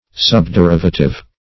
Subderivative \Sub`de*riv"a*tive\, n.